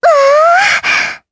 Erze-Vox_Happy4_jp.wav